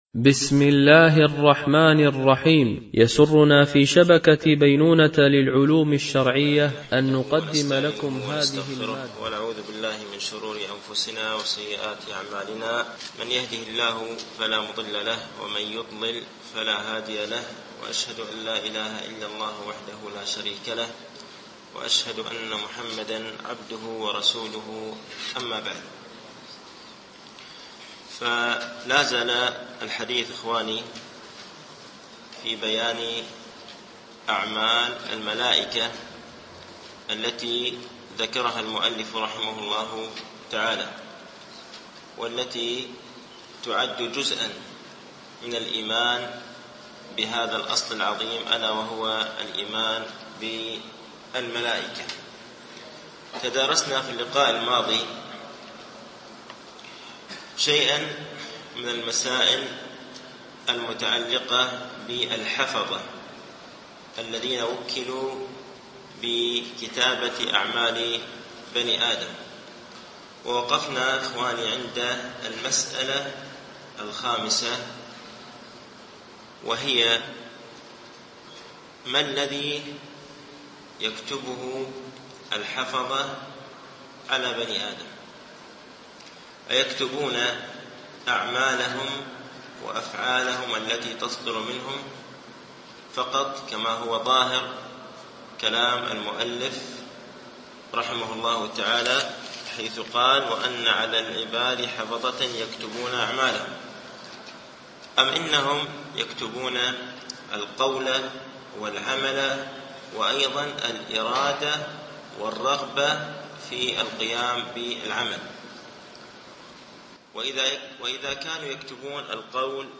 شرح مقدمة ابن أبي زيد القيرواني ـ الدرس الثامن و السبعون